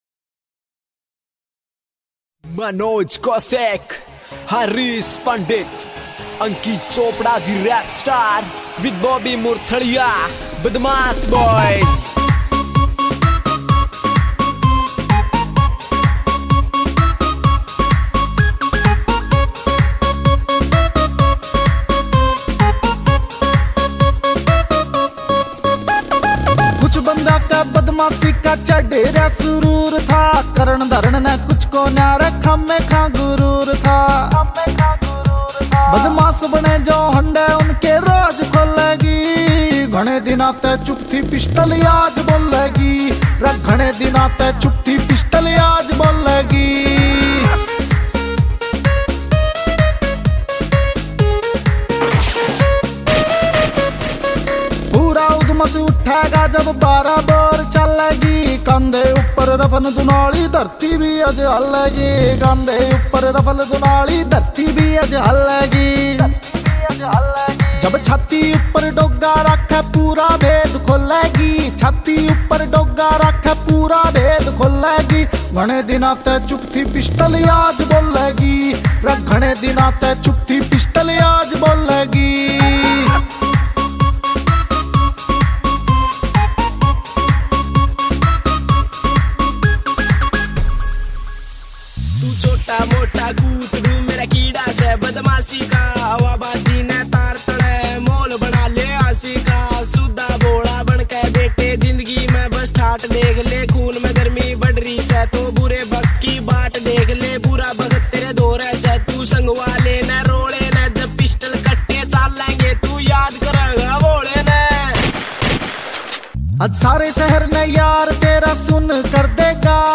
[ Haryanvi Songs ]